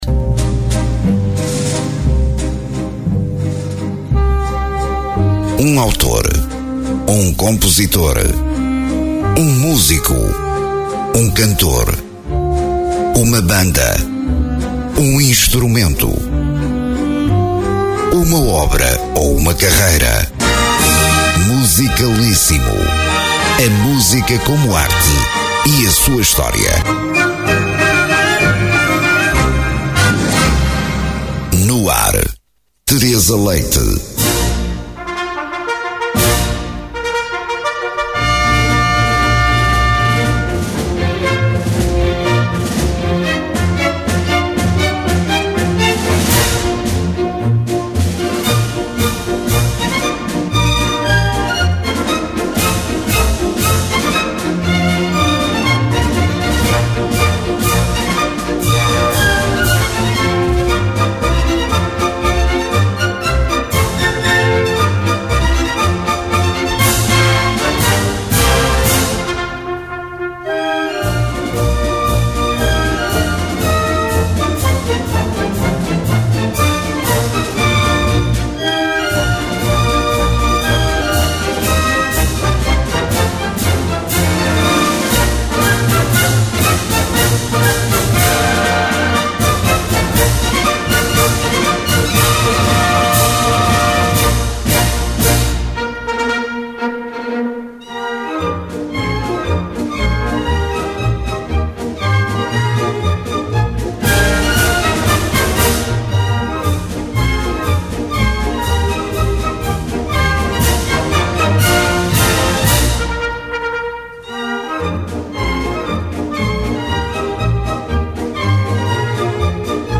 valsas vienenses
Polcas, Valsas e Marchas. Começámos com a marcha de entrada de “El Barón Gitano”, uma Polca “Sangre Ligera” e “Perpetuum Mobile,” três peças leves, alegres e a respirar natureza.
Segue-se agora uma polca bem rápida e o indispensável Danúbio Azul, a mais bela valsa de sempre, que todos conhecemos e nunca cansamos de ouvir.